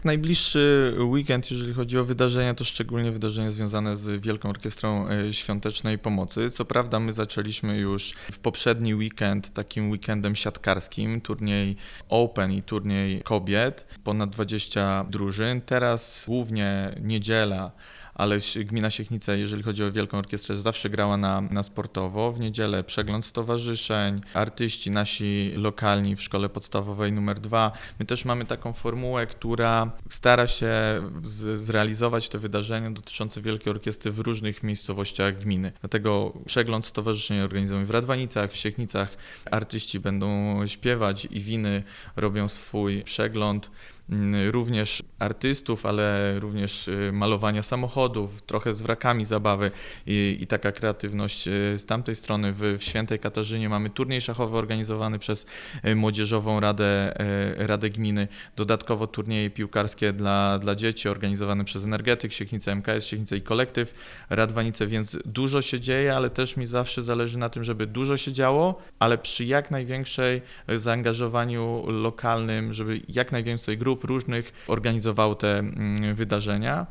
O tym, jak będzie przebiegał finał WOŚP w gminie mówi burmistrz Łukasz Kropski.